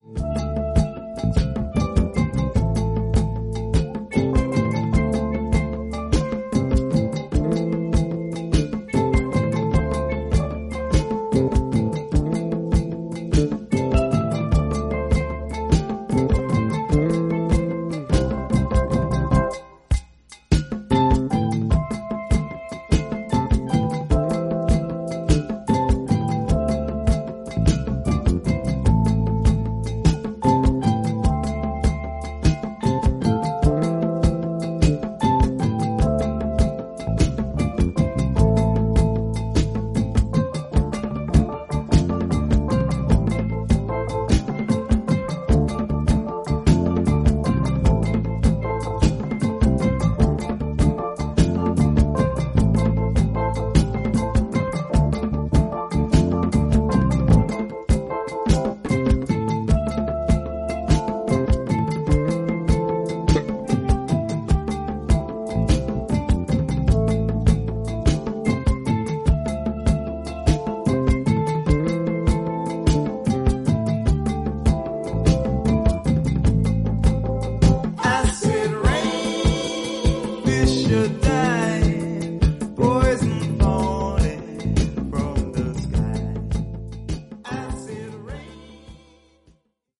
UKアフリカン/カリビアン混成バンド